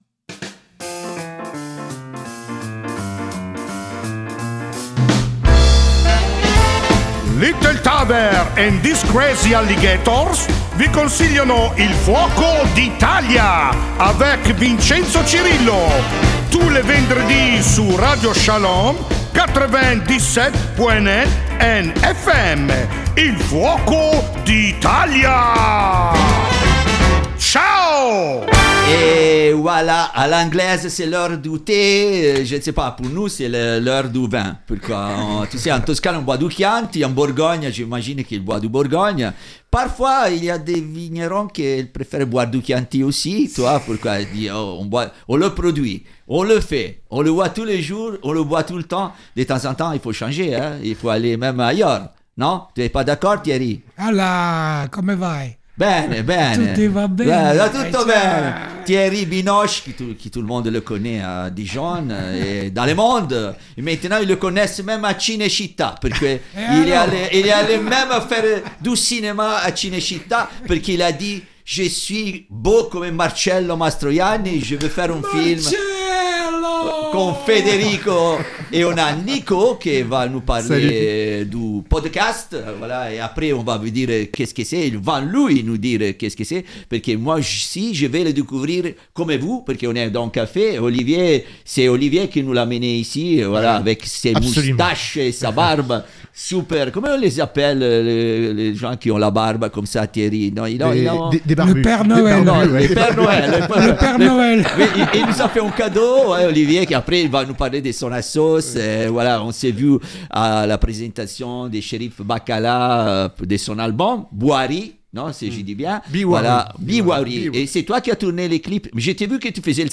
Les studios de Radio Shalom étaient encore en feu pour ce nouvel épisode de votre émission '' Il Fuoco d'Italia